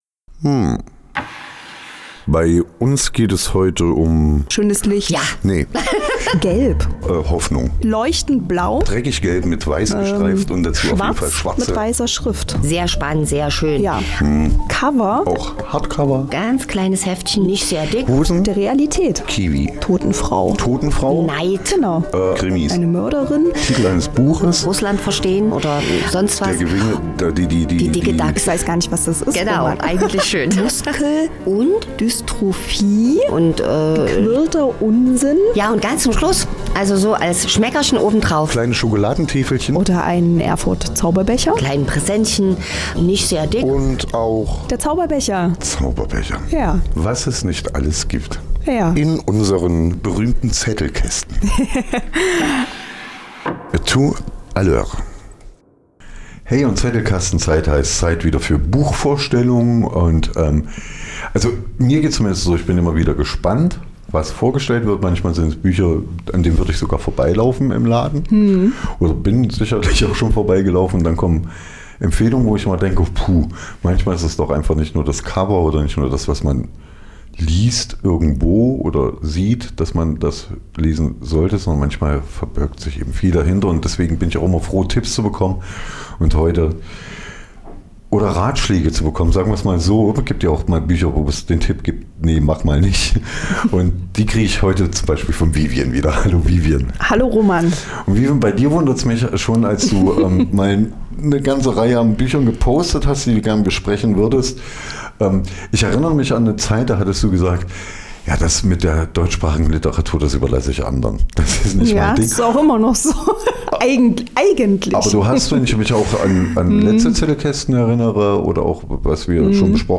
Zettelkasten - die aktuelle Buchbesprechung | Petra Pellini - Der Bademeister ohne Himmel